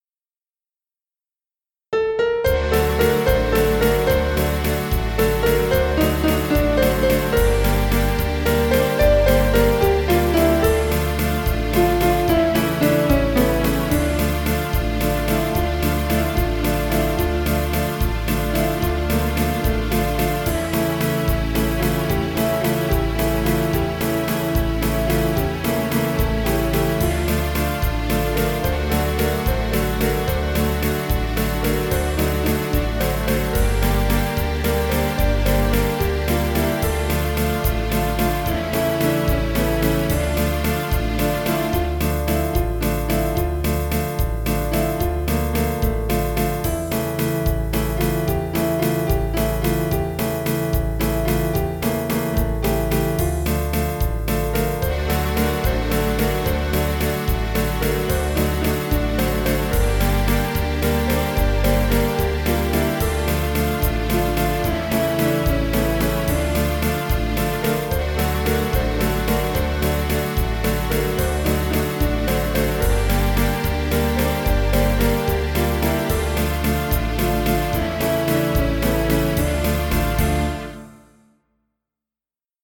mp3 (минус)